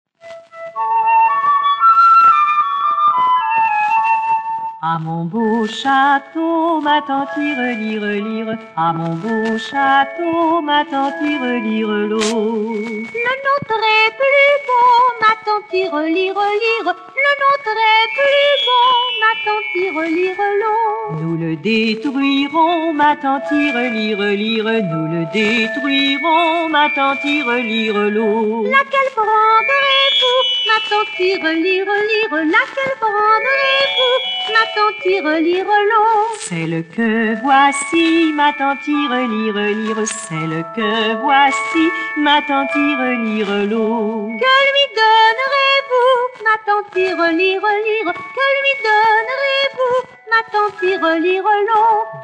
14 chansons et comptines pour enfants
Enregistrement original